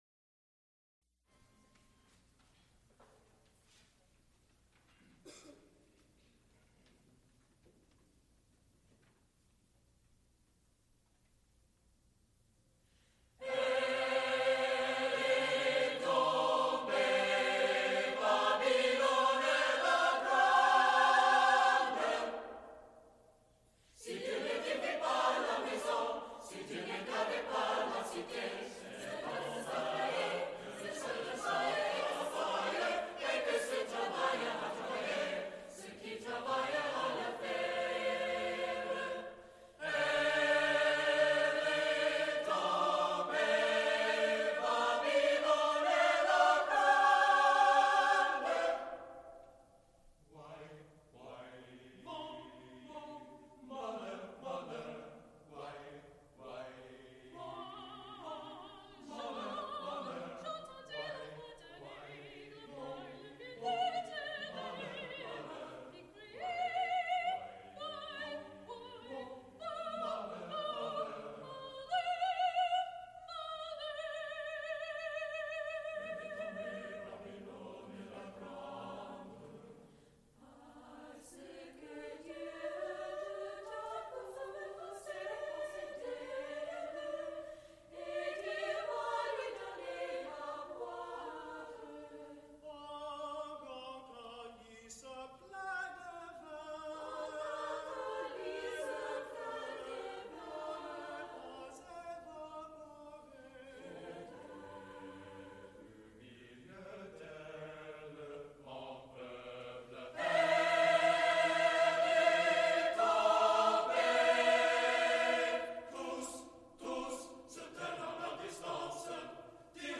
Recorded live February 2, 1978, Heinz Chapel, University of Pittsburgh.
Extent 1 audiotape reel : analog, half track,stereo, 7 1/2 ips ; 7 in.
musical performances
Choruses, Sacred (Mixed voices), Unaccompanied Choruses, Secular (Mixed voices) with organ